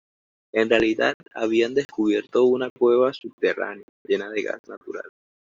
Pronounced as (IPA) /ˈkweba/